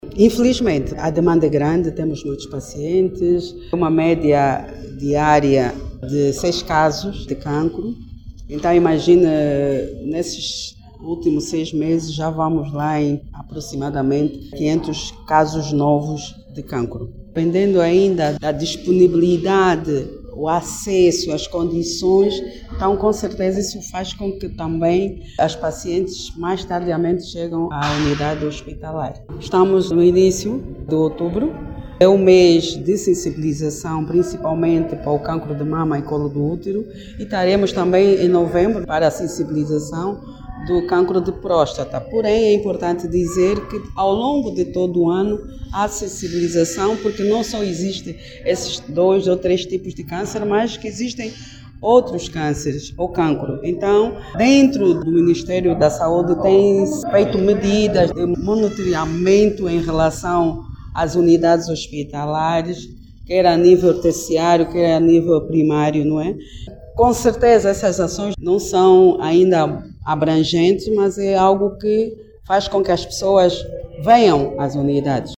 durante a cerimónia de abertura da campanha Outubro Roas